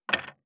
582まな板の上,包丁,ナイフ,調理音,料理,
効果音厨房/台所/レストラン/kitchen食器食材